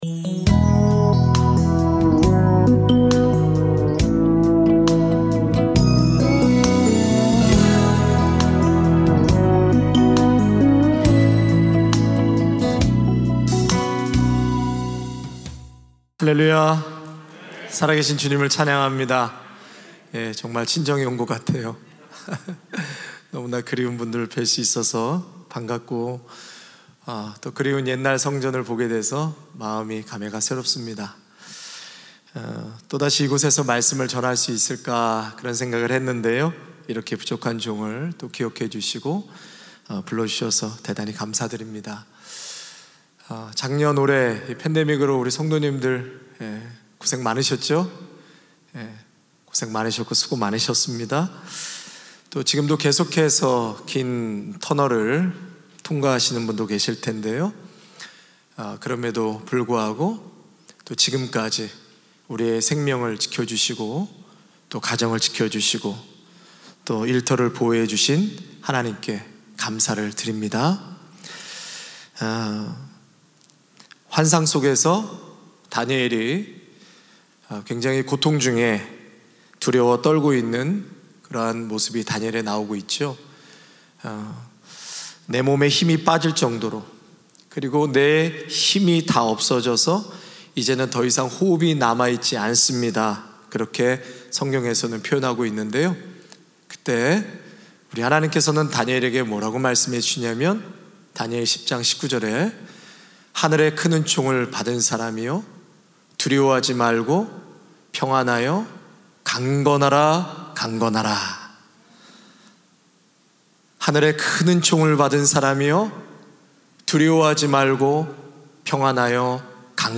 특별 새벽 집회